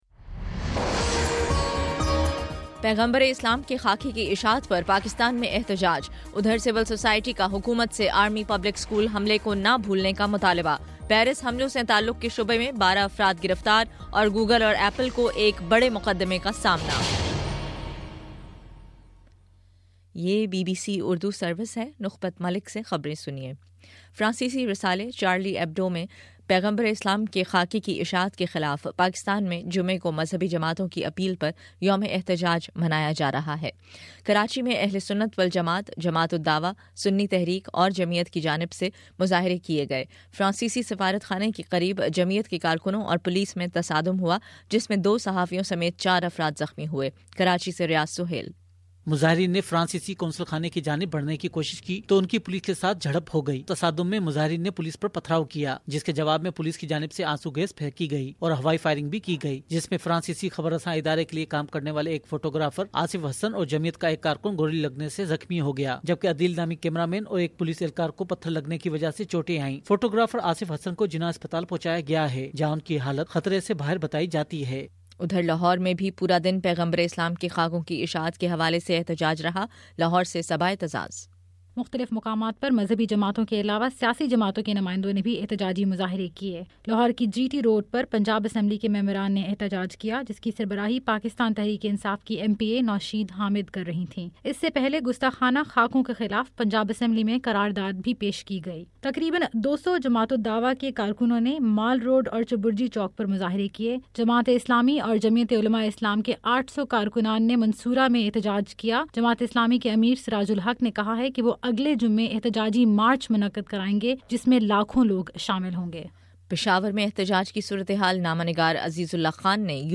جنوری 16: شام چھ بجے کا نیوز بُلیٹن